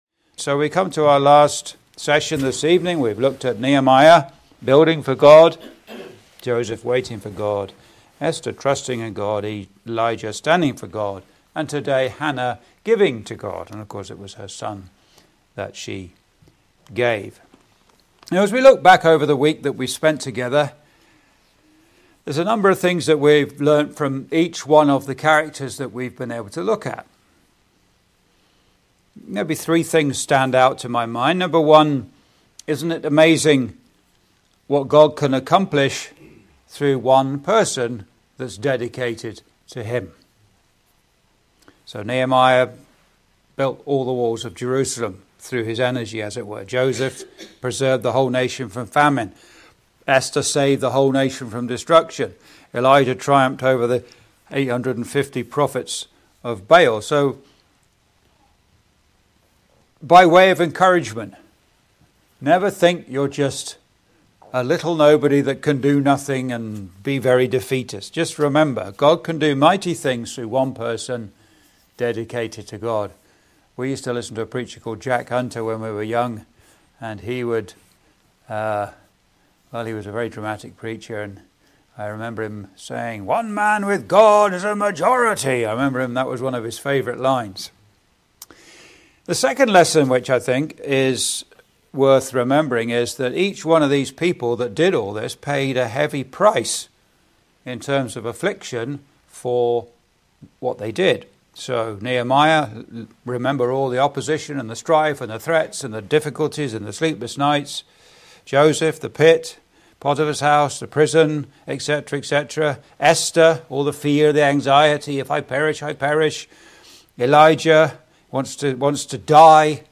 (Recorded in The Malden Road Gospel Hall, Windsor, ON, Canada on 14th Jan 2026) Complete series: Nehemiah – Building for God
Video Ministry